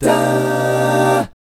1-CMI7  AA-R.wav